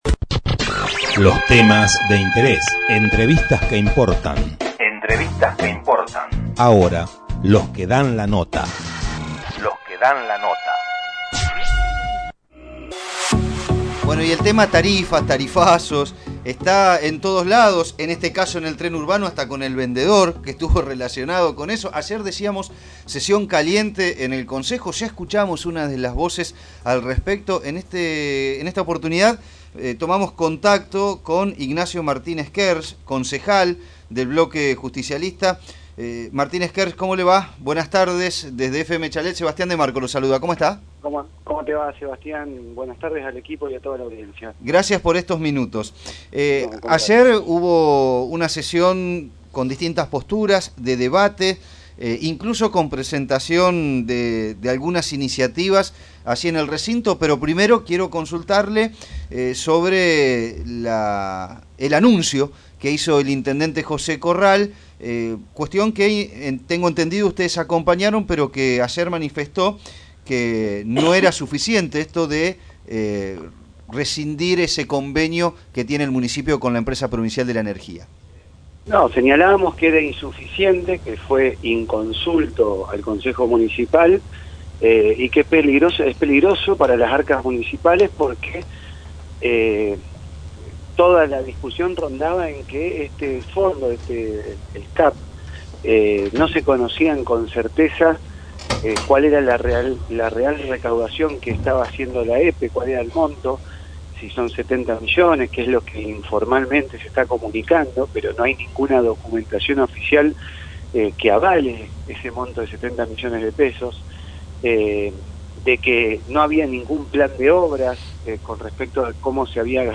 Así se expresaba el Concejal Ignacio Martinez Kerz, que en dialogo con Tren Urbano detalló las implicancias del aumento de tarifas de la energía eléctrica y puso de manifiesto un pedido al gobierno nacional de eliminar el IVA en las facturas de servicios públicos con amplio apoyo de todos los bloques legislativos.